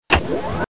Elevator up 2
Tags: Elevator Sounds Elevator Elevator Sound clips Elevator sound Sound effect